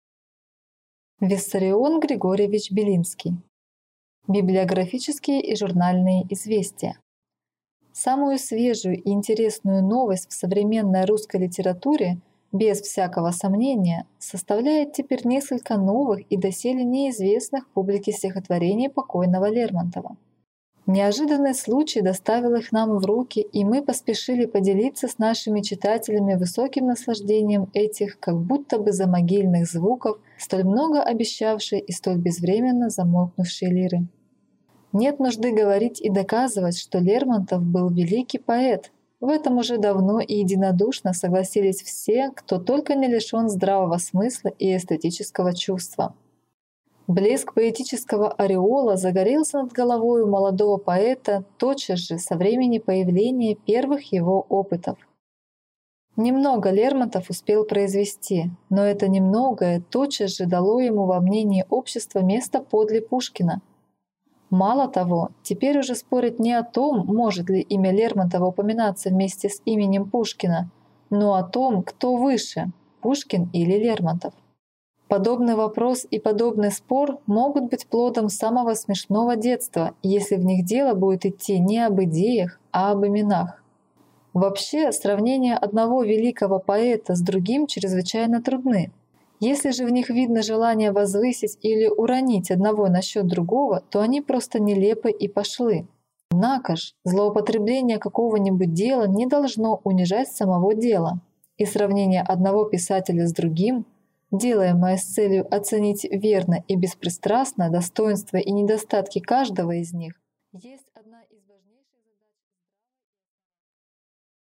Аудиокнига Библиографические и журнальные известия | Библиотека аудиокниг